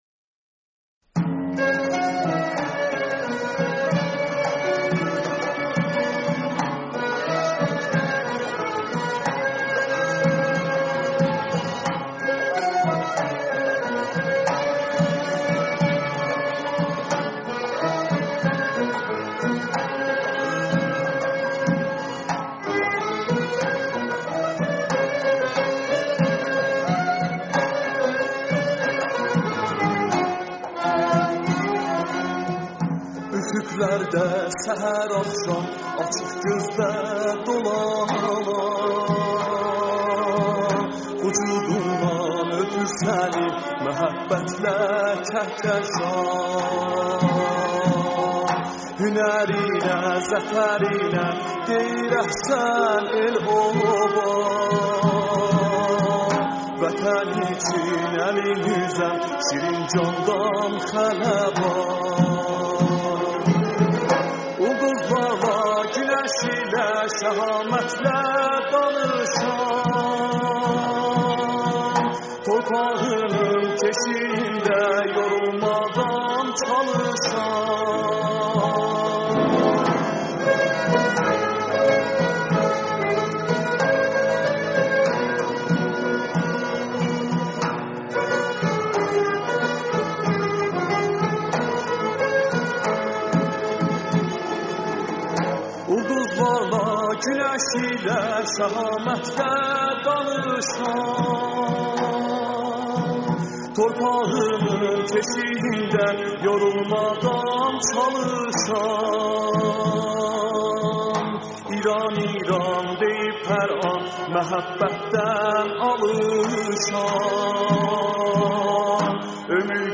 ترانه آذری با مضمون دفاع مقدس